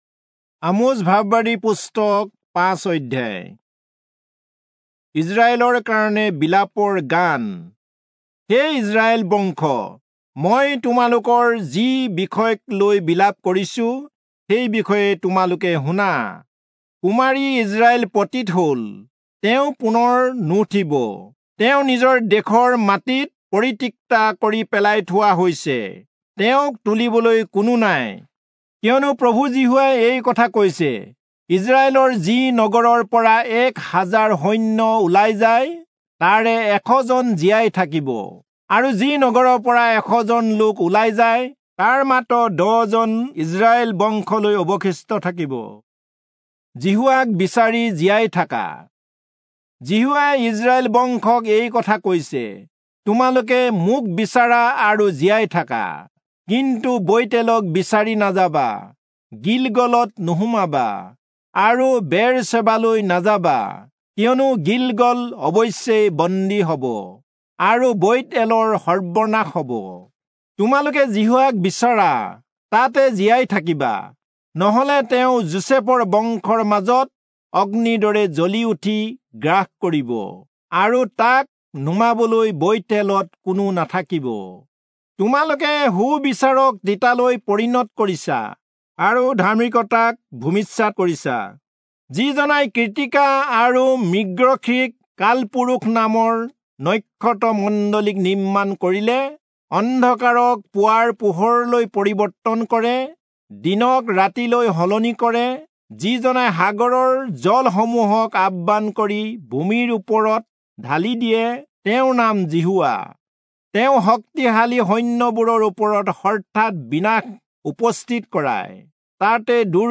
Assamese Audio Bible - Amos 8 in Mkjv bible version